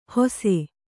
♪ hose